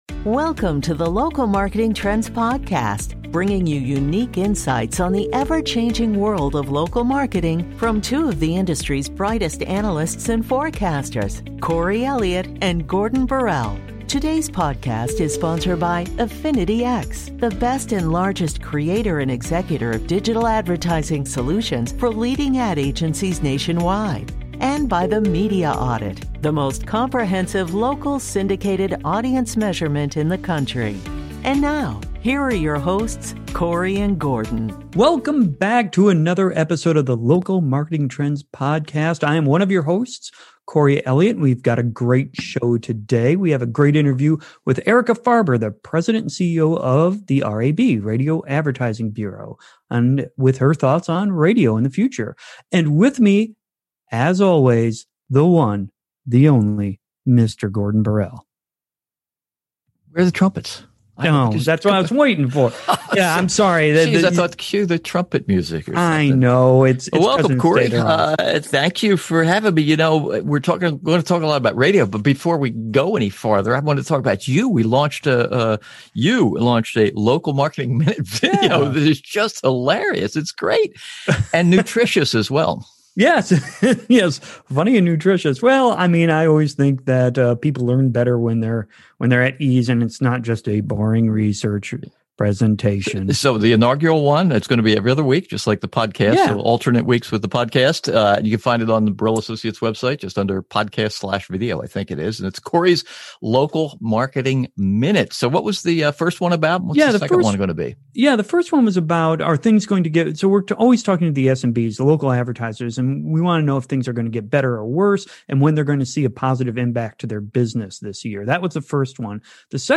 The episode features an interview